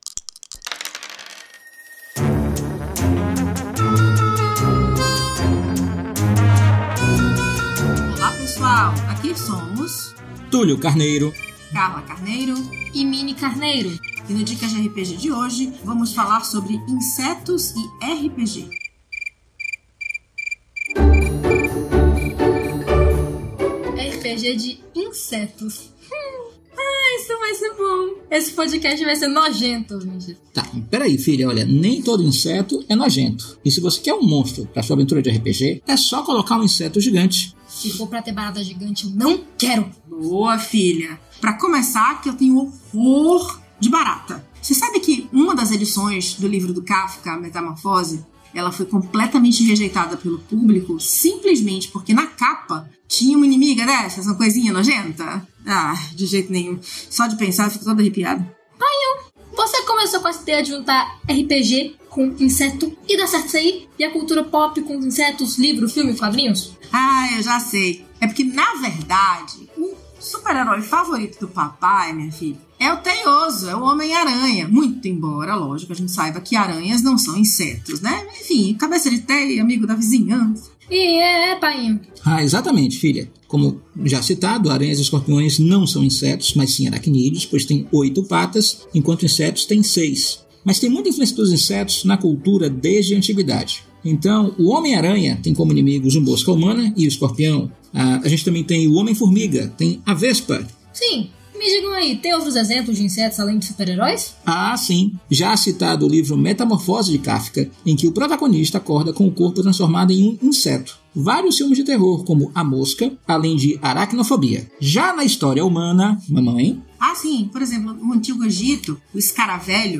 O Dicas de RPG é um podcast semanal no formato de pílula que todo domingo vai chegar no seu feed.
Músicas: Music by from Pixabay